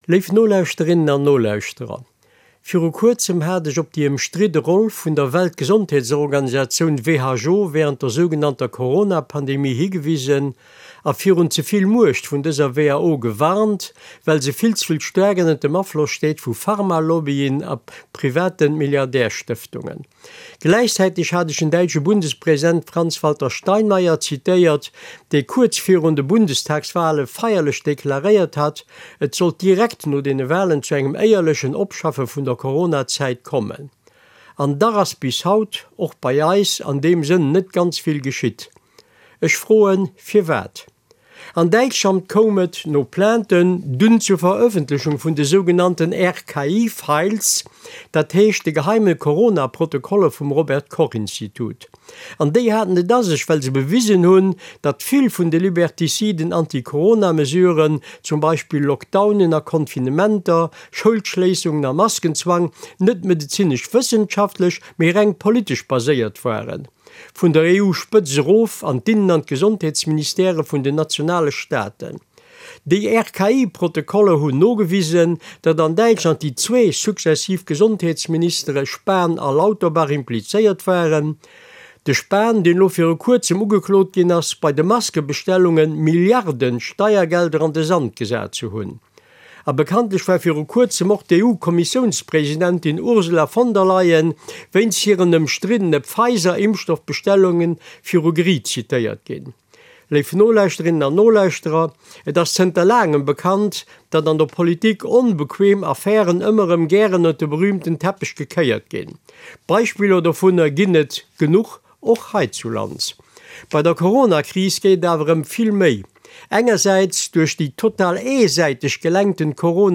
A senger Carte Blanche schwätzt de Jean Huss, ex-Deputéierten, iwwer en noutwendege Bilan vu fënnef Joer Corona.